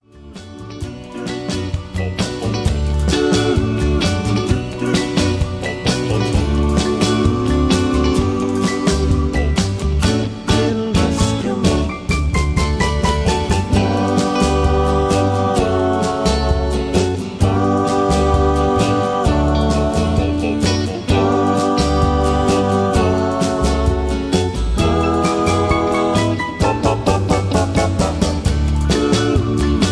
(Version-1, Key-D) Karaoke MP3 Backing Tracks
Just Plain & Simply "GREAT MUSIC" (No Lyrics).